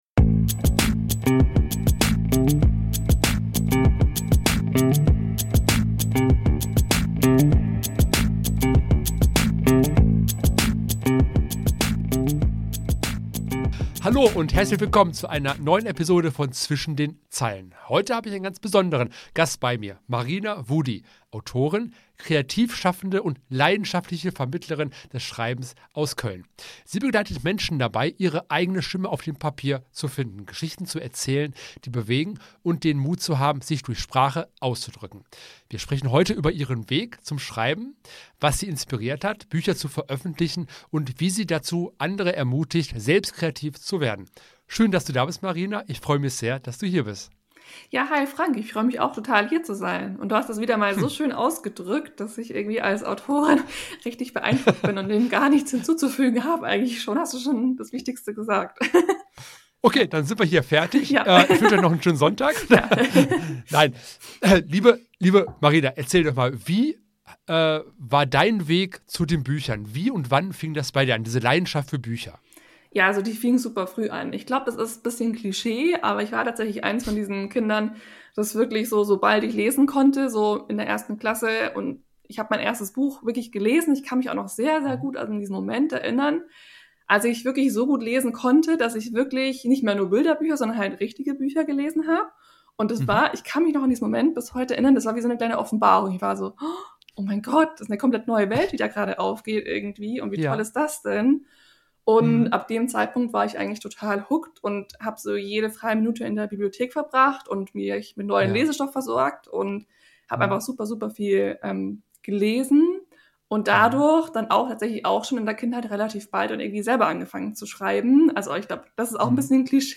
Eine exklusive Lesung zum Abschluss